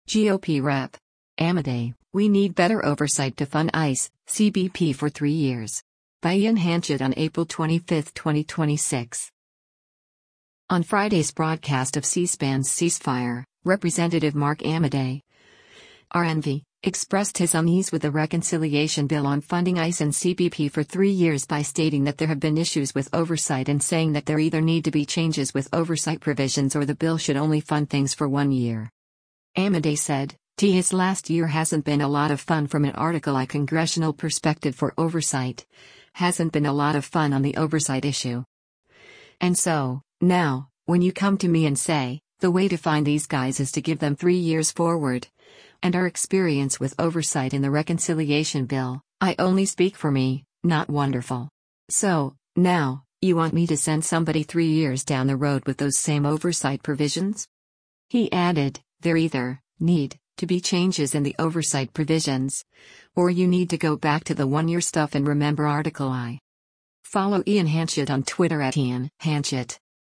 On Friday’s broadcast of C-SPAN’s “Ceasefire,” Rep. Mark Amodei (R-NV) expressed his unease with a reconciliation bill on funding ICE and CBP for three years by stating that there have been issues with oversight and saying that there either need to be changes with oversight provisions or the bill should only fund things for one year.